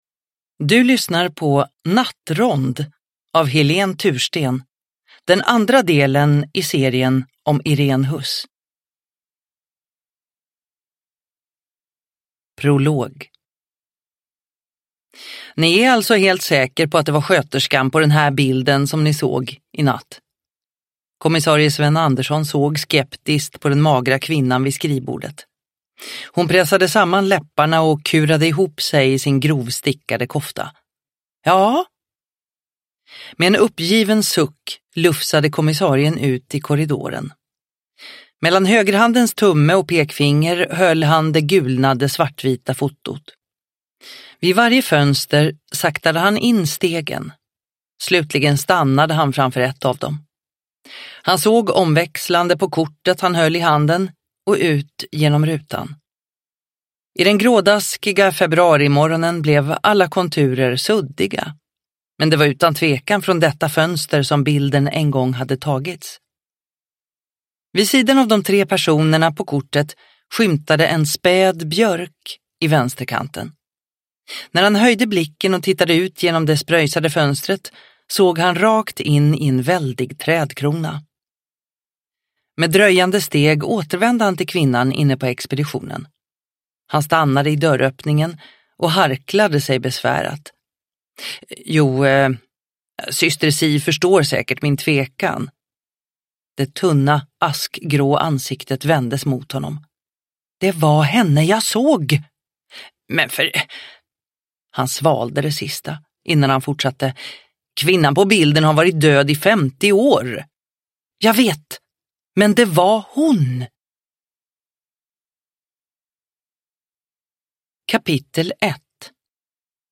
Nattrond – Ljudbok – Laddas ner